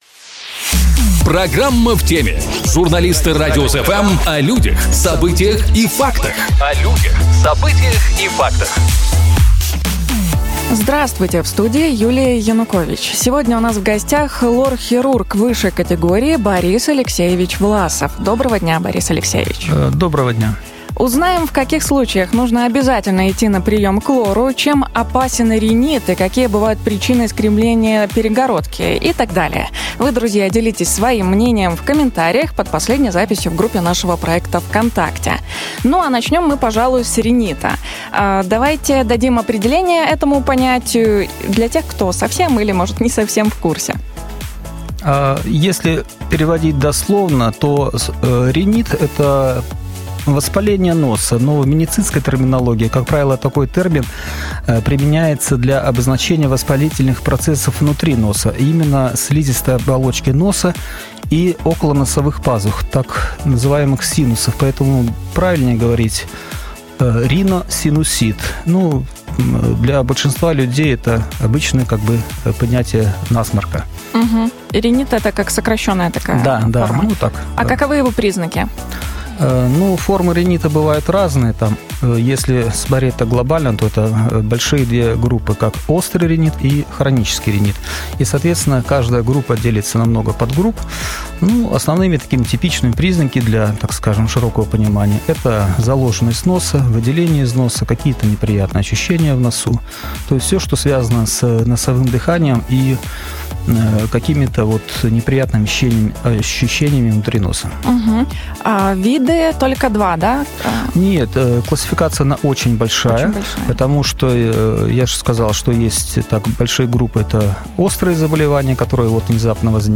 Эти и другие вопросы мы обсудим сегодня с нашим гостем. Сегодня у нас в студии